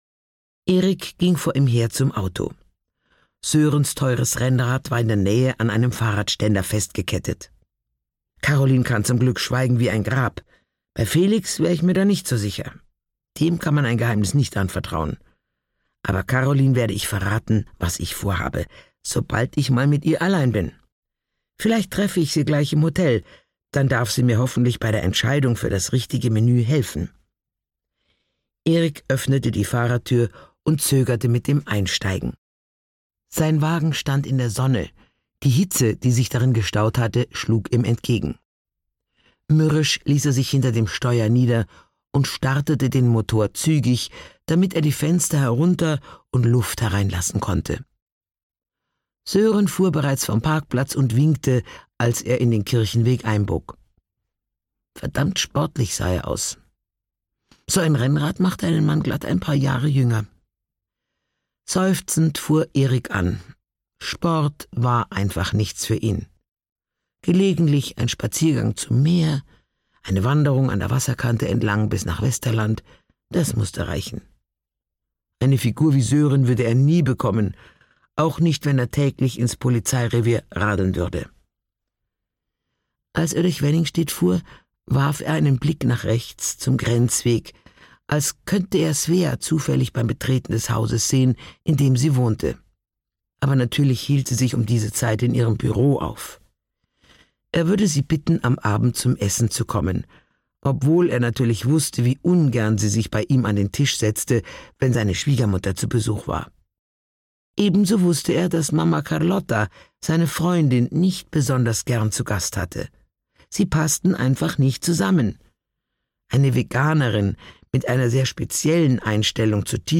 Wellenbrecher (Mamma Carlotta 12) - Gisa Pauly - Hörbuch